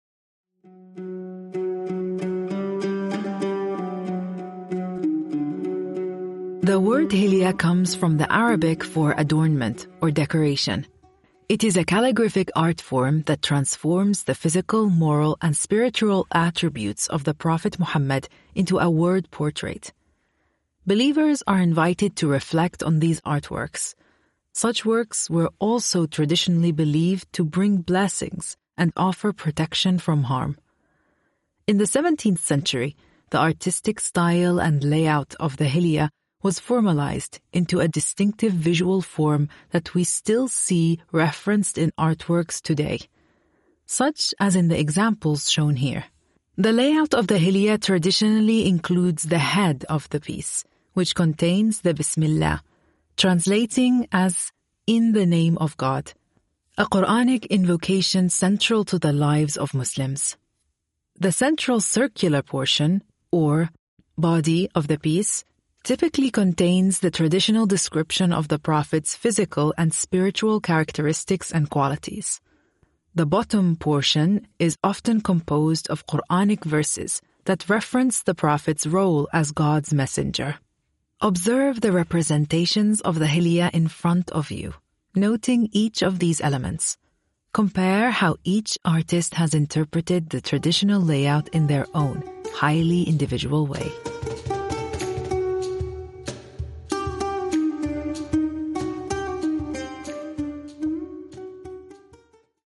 Tief, Cool, Sanft
Erklärvideo